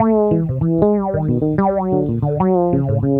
Bass Lick 35-03.wav